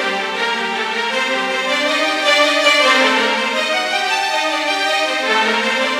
Rising Strings.wav